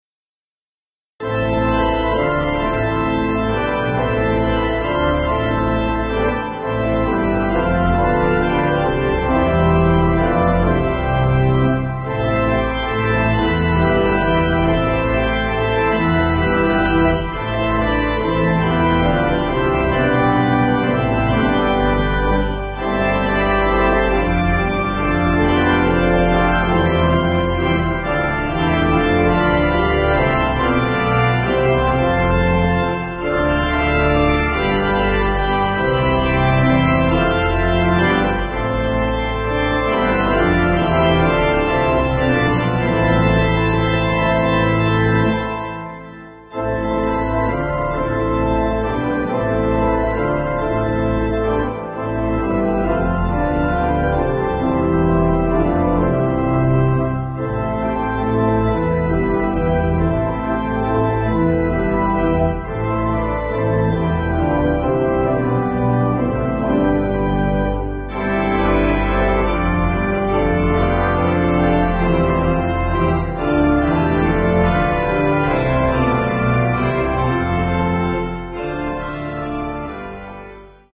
(CM)   4/Am